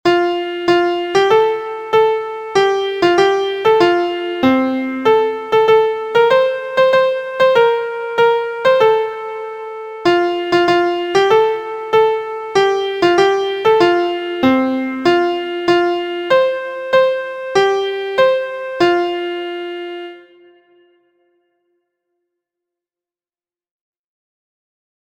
• Origin: USA – Traditional
• Key: F Major
• Time: 2/4
• Form: ABAC